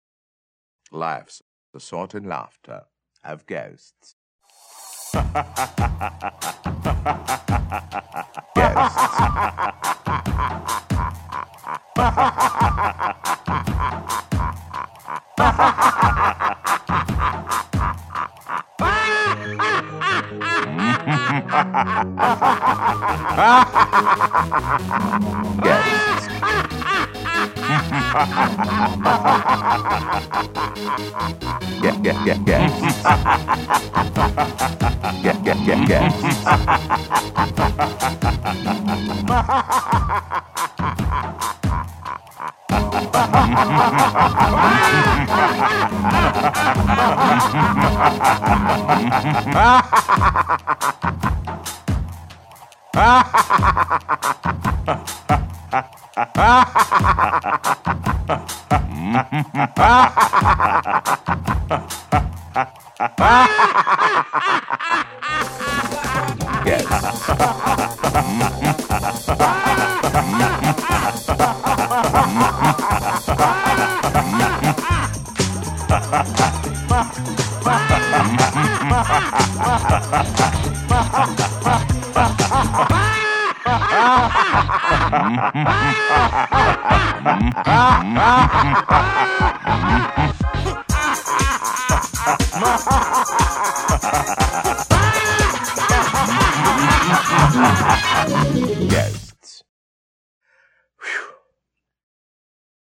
Halloween is nearly upon us, and you know what that means: time for novelty Halloween songs!
Ahhhh-ha-ha-ha-ha-ha-ha-ha!
Ghosts (voiced by Paul Frees) laughing, set to a beat you can move to.